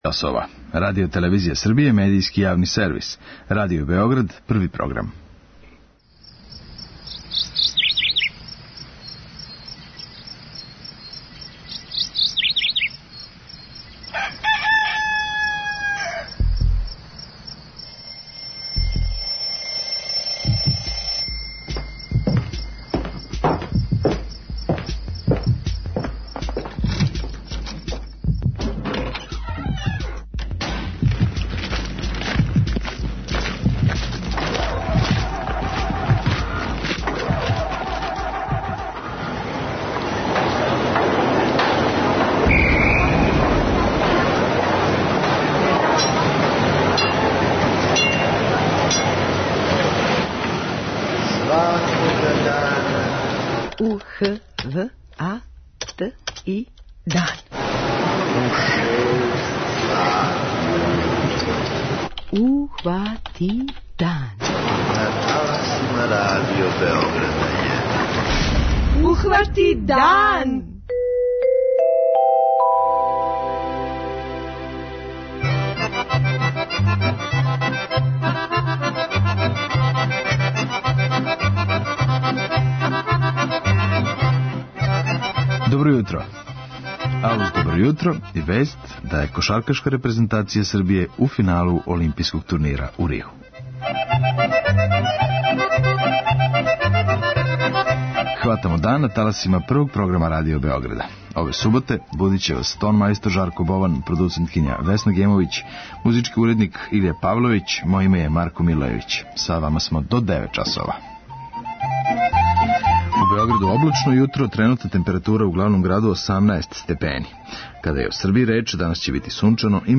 И овог јутра чућемо извештај наших репортера са Олимпијских игара у Бразилу.